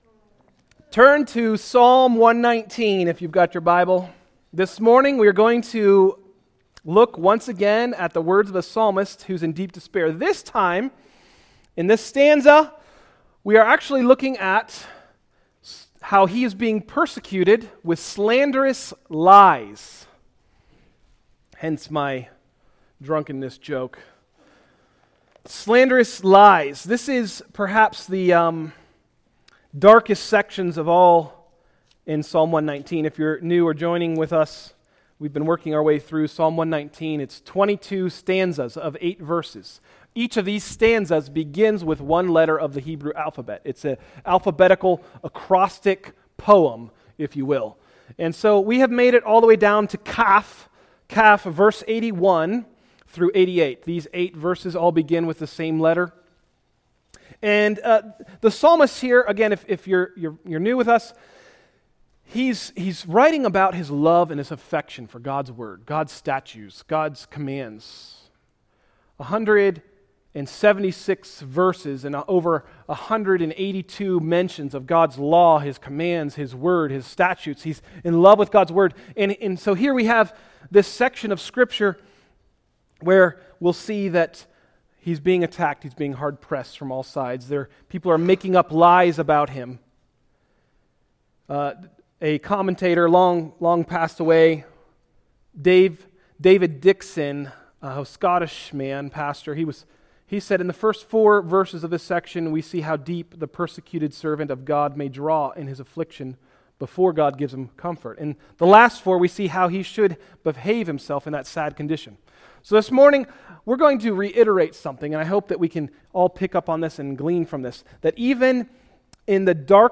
Message: “Kaph: Psalm 119” – Tried Stone Christian Center